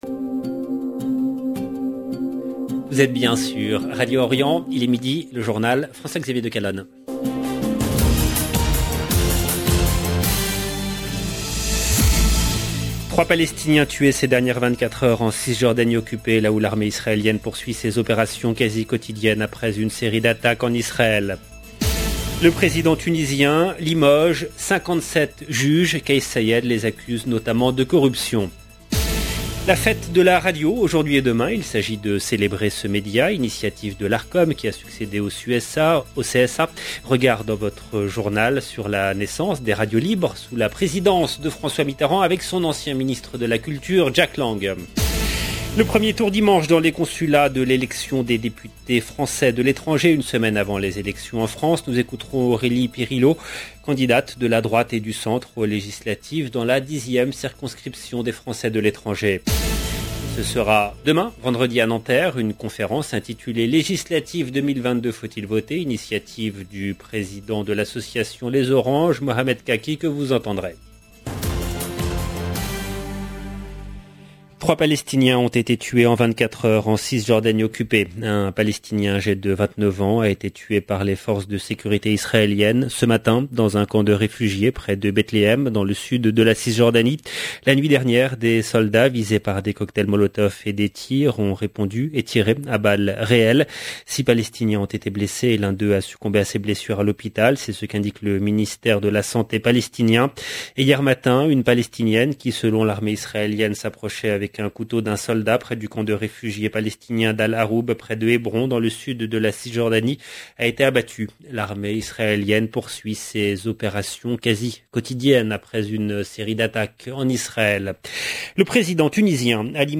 EDITION DU JOURNAL DE 12 H EN LANGUE FRANCAISE DU 2/6/2022